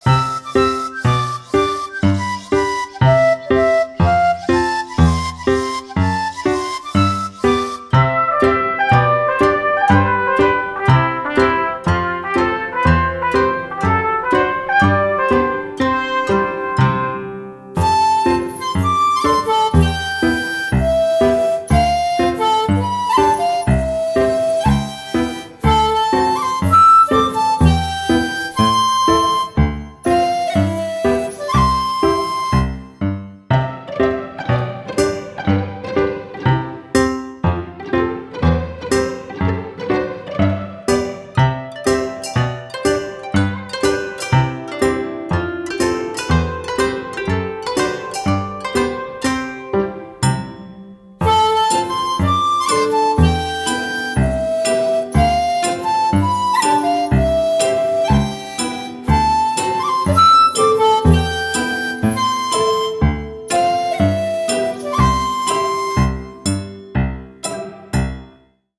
Japanese style music with a light and fun pop atmosphere.
Japanese style music No copyright music